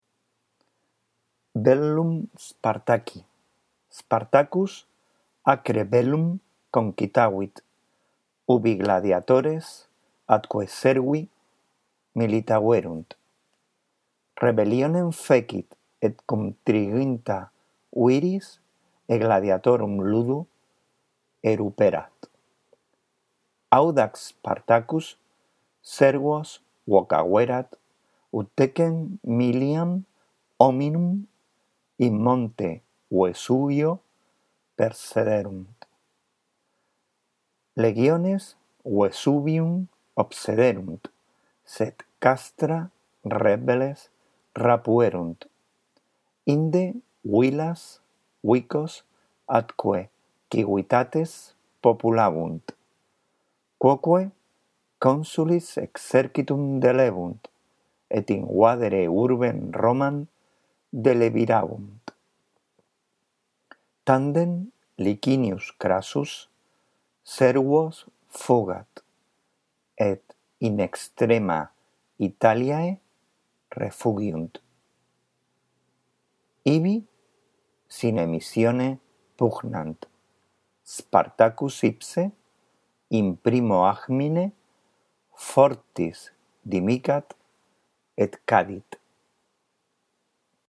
Tienes una lectura justo debajo del texto por si la necesitas.
Después de escuchar con atención nuestra lectura del texto latino, lee tú despacio procurando pronunciar correctamente cada palabra y entonando cada una de las oraciones.